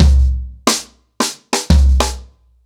Wireless-90BPM.31.wav